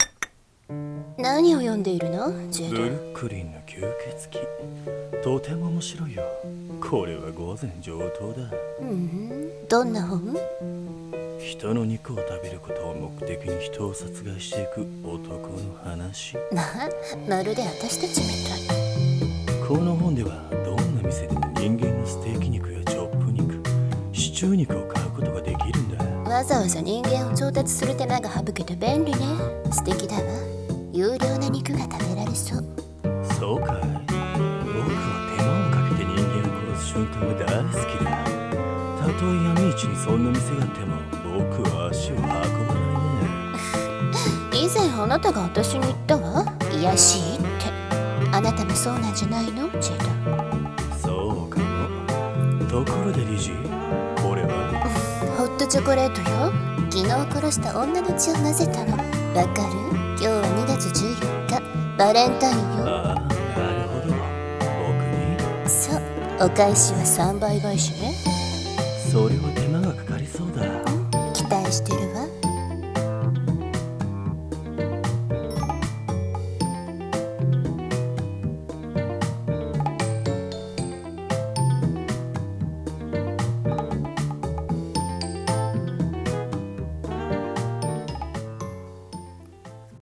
【声劇】Chocolate with Love 【掛け合い】